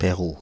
Perrou (French pronunciation: [pɛʁu]
Fr-Perrou.ogg.mp3